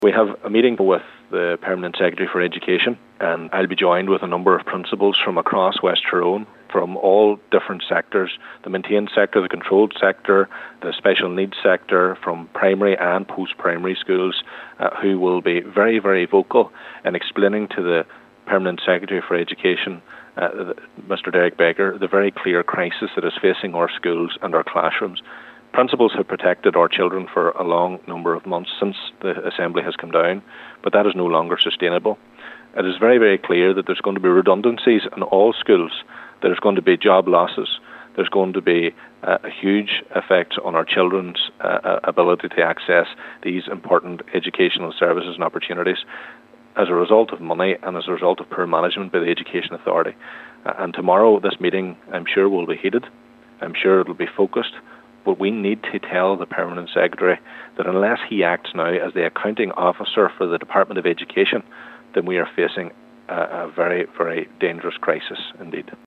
West Tyrone MLA Daniel McCrossan says action needs to be taken by the Department of Education before the crisis escalates further: